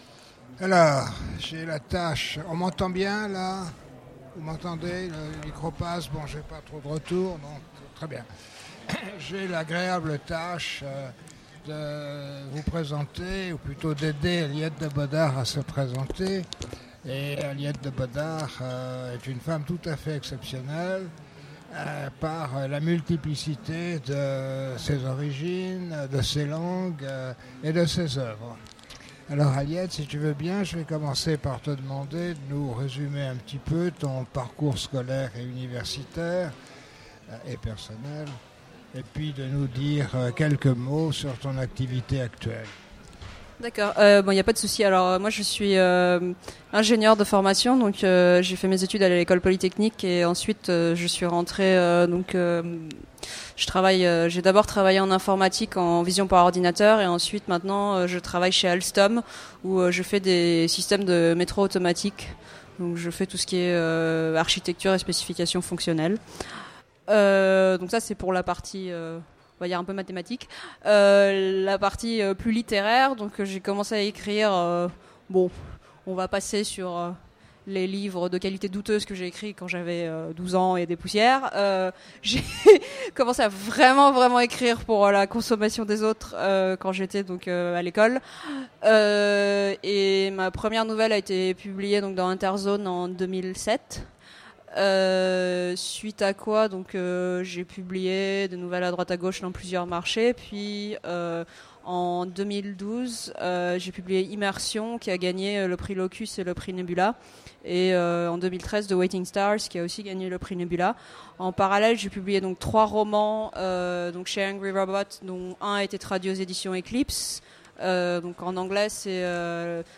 Utopiales 2015 : Rencontre avec Aliette de Bodard - ActuSF - Site sur l'actualité de l'imaginaire
- le 31/10/2017 Partager Commenter Utopiales 2015 : Rencontre avec Aliette de Bodard Télécharger le MP3 à lire aussi Aliette de Bodard Genres / Mots-clés Rencontre avec un auteur Conférence Partager cet article